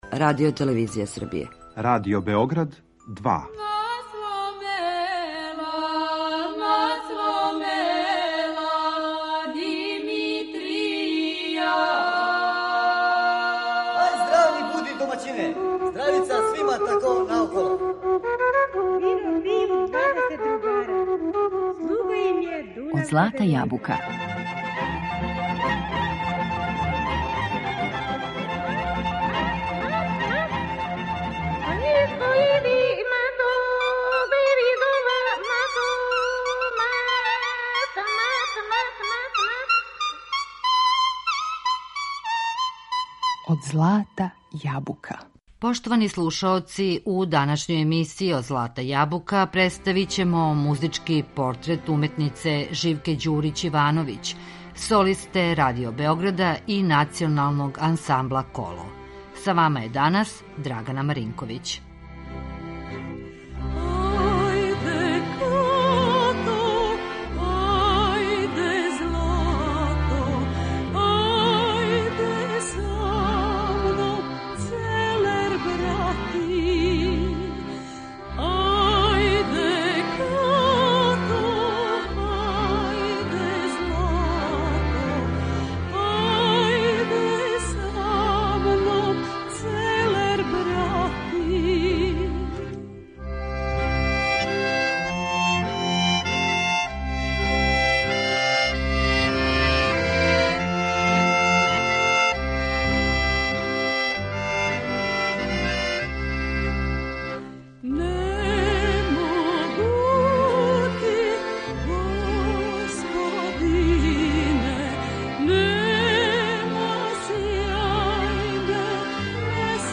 У емисији Од злата јабука представићемо музички портрет уметнице Живке Ђурић Ивановић, солисткиње Радио Београда и Ансамбла народних игара и песама Србије „Коло'.
Била је драгоцен етномузиколог аматер, а за Архив Радио Београда снимила је велики број трајних записа најлепших изворних и градских песама, као и бројне песме широких балканских простора. Живка Ђурић Ивановић је у уметничком свету једно од највећих имена које је српска народна музика имала.